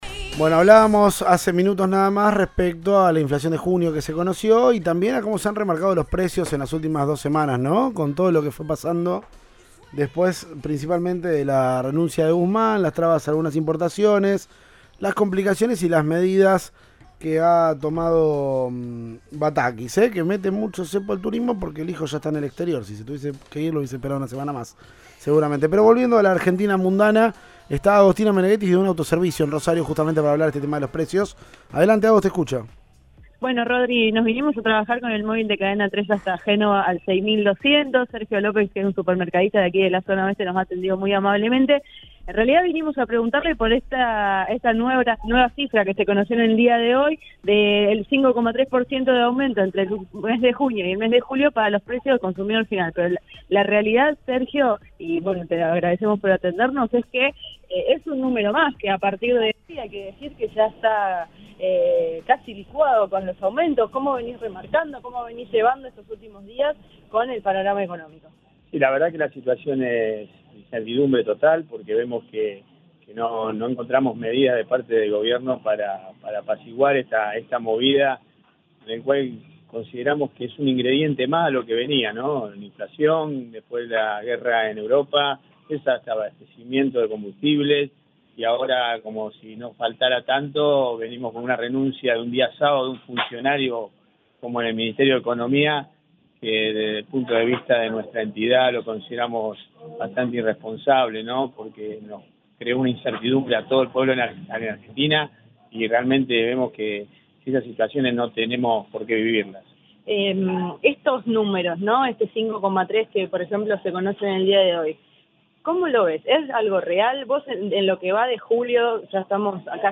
Luego de que el Indec publicara el dato de inflación de junio (5,3%), que lleva el aumento promedio al 36,3% en el primer semestre, Cadena 3 Rosario habló con dueño y clientes desde un autoservicio.